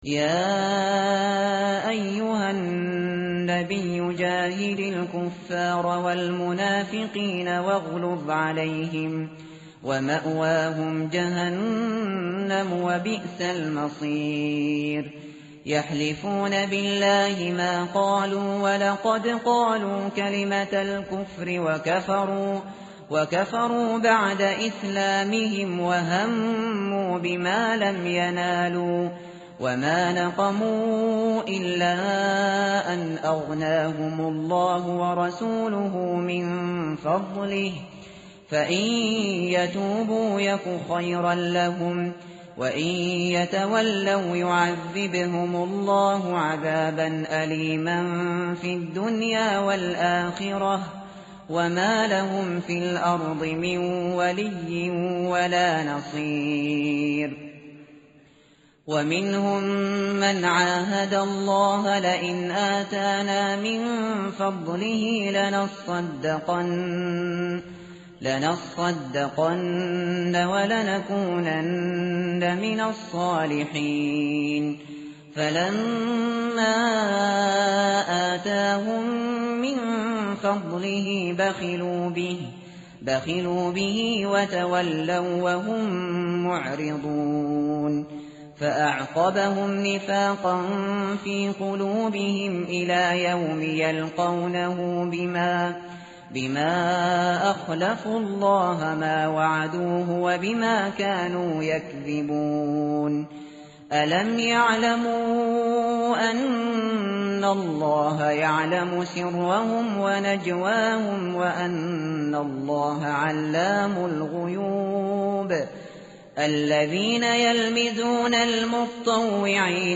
tartil_shateri_page_199.mp3